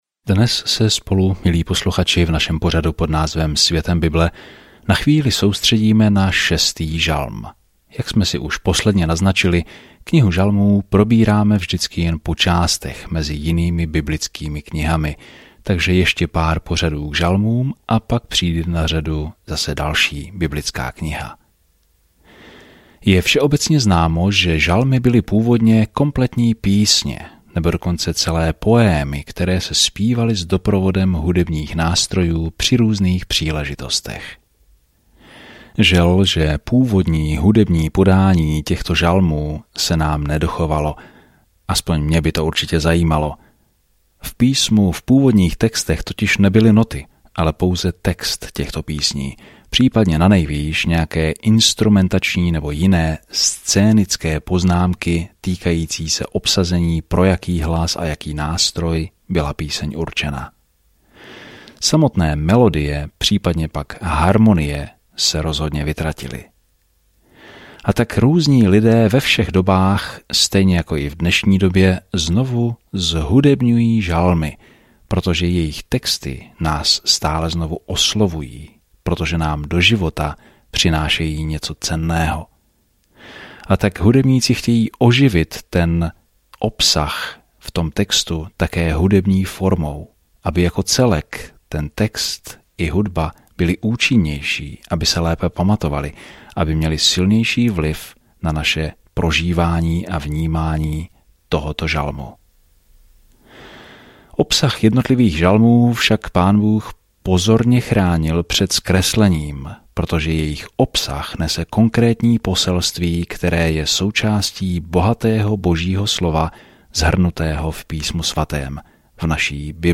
Denně procházejte žalmy, poslouchejte audiostudii a čtěte vybrané verše z Božího slova.